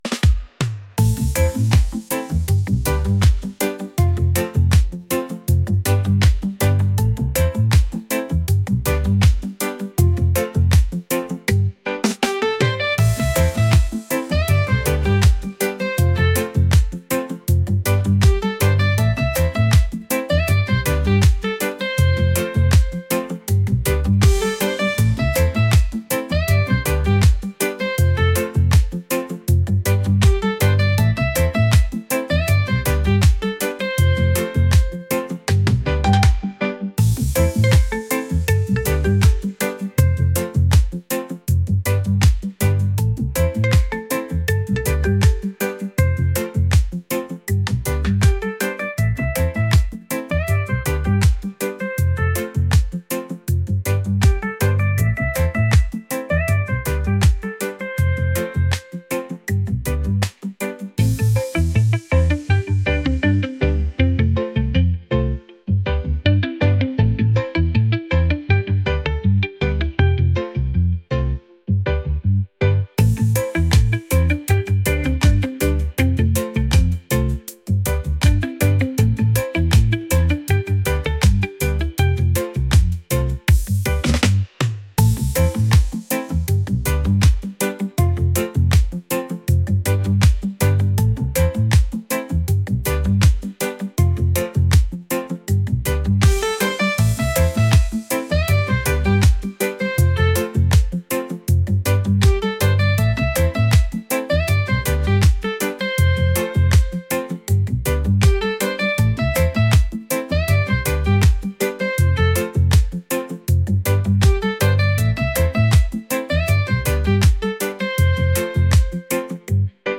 relaxed | reggae